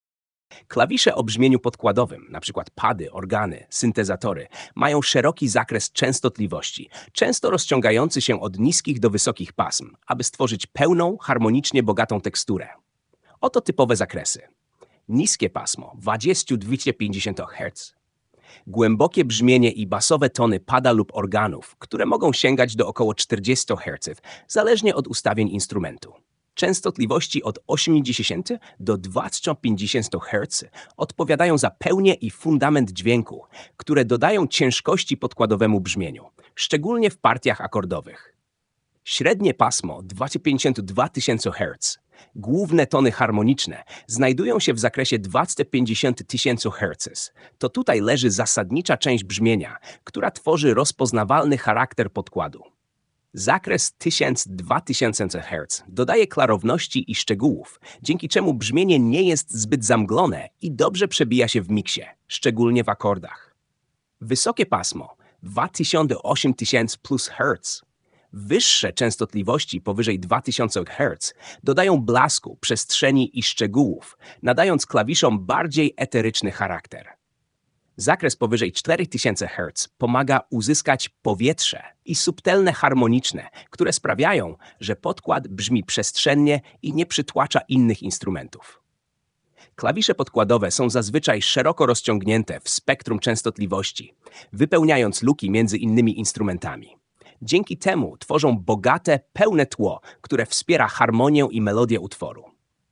Klawisze pasmo akustyczne
lektor
Klawisze-pasmo.mp3